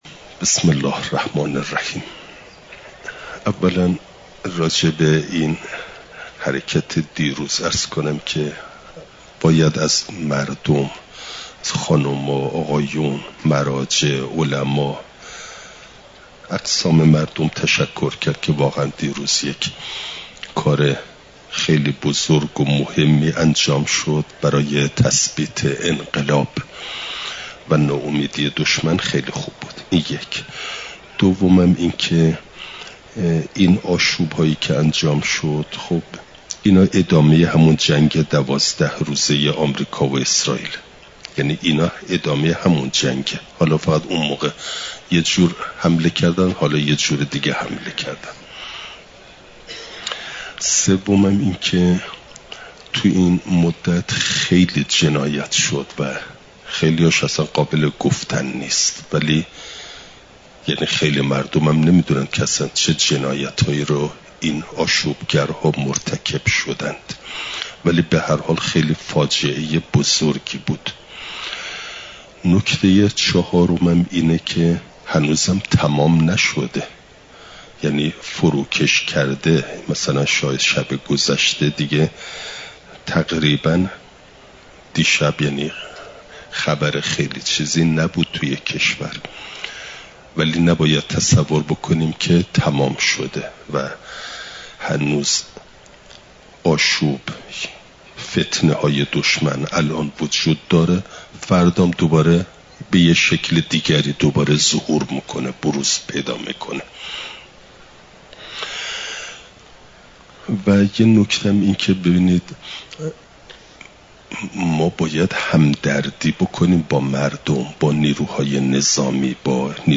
سه شنبه ۲۳دیماه ۱۴۰۴، حرم مطهر حضرت معصومه سلام ﷲ علیها